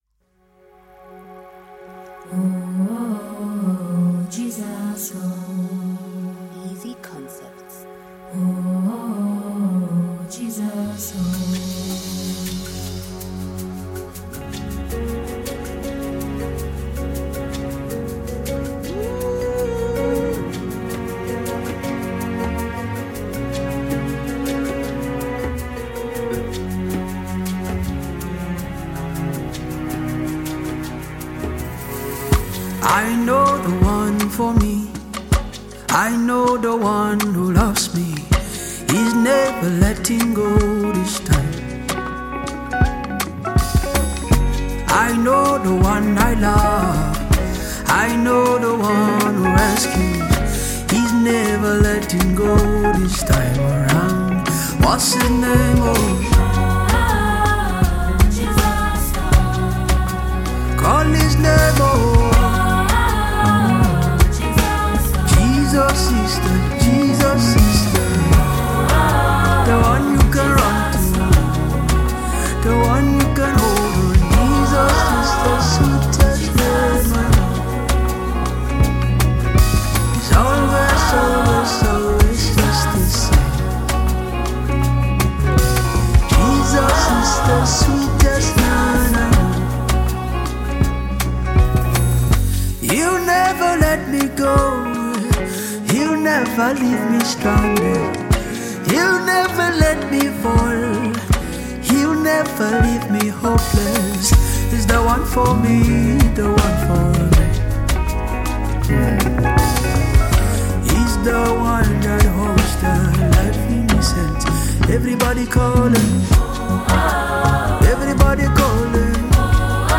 blends Afrobeat with contemporary sounds
With its upbeat tempo and catchy sounds